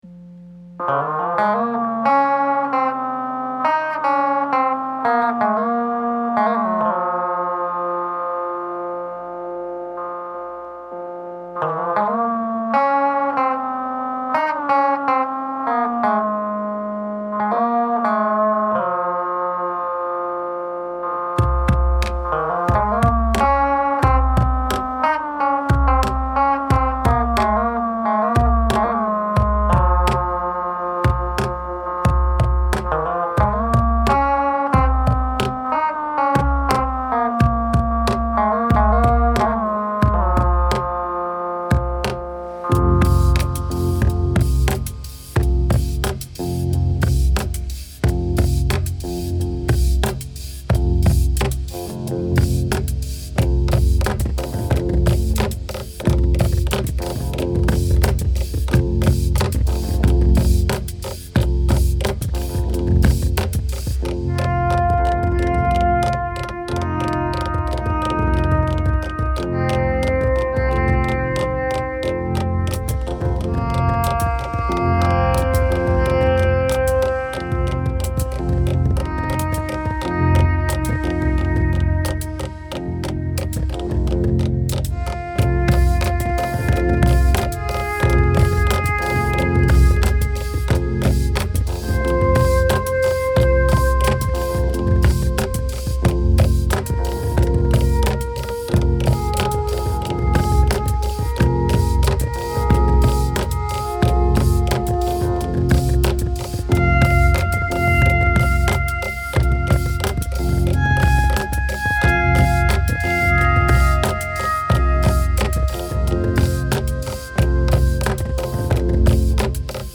Tag: electric guitar